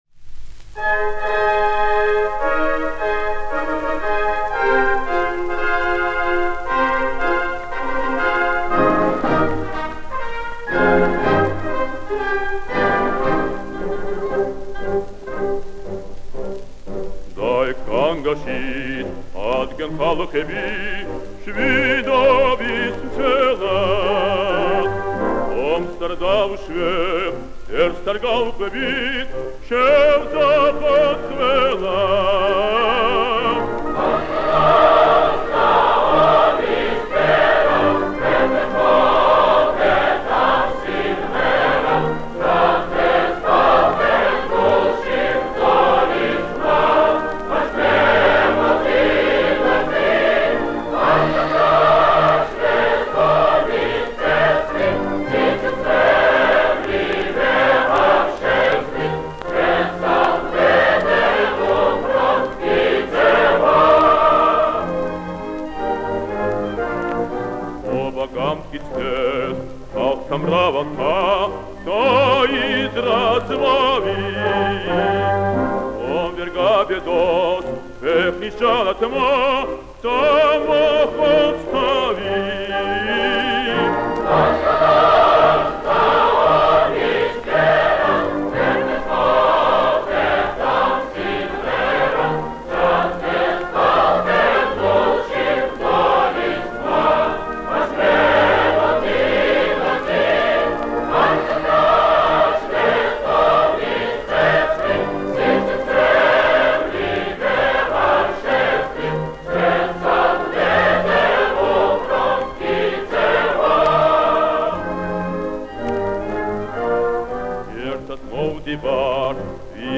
Качественное исполнение на грузинском языке.
оркестр